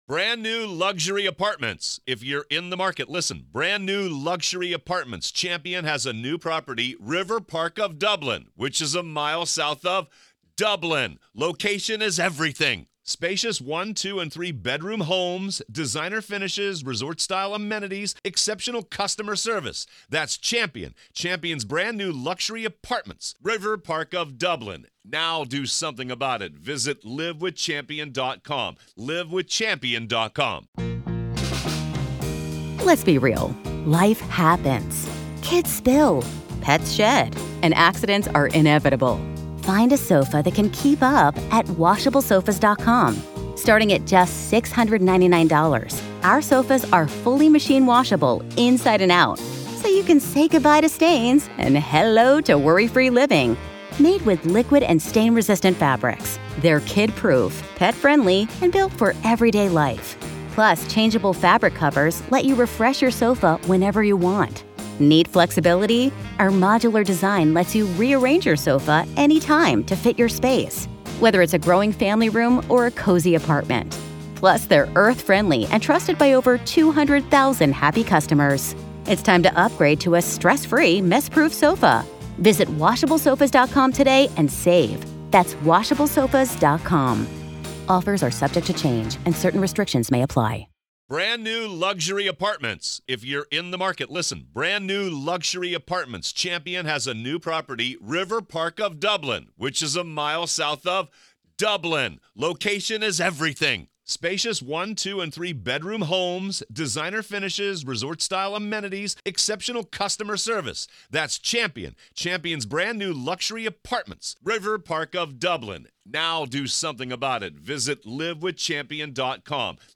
Alex Murdaugh Trial: Courtroom Coverage | Day 4, Part 2